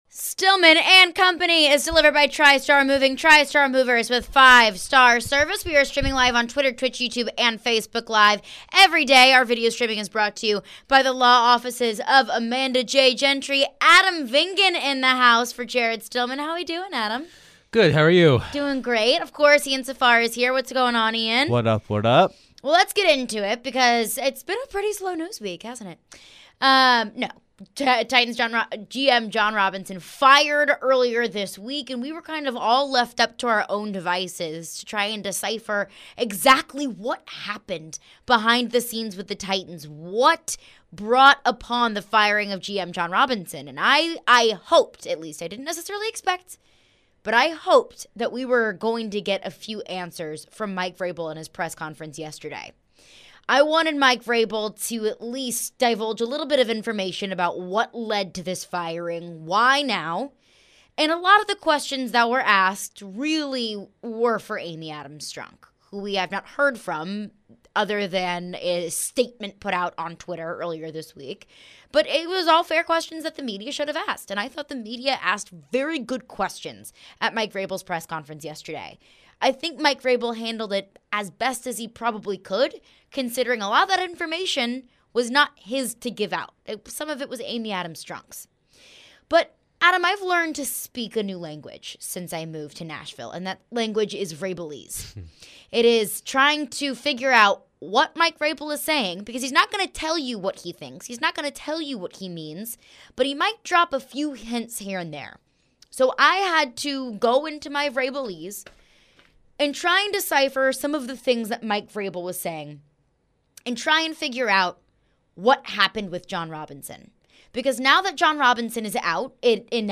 We're still trying to figure out the timing of the decision. Caleb Farley is out for the season and we take your phones.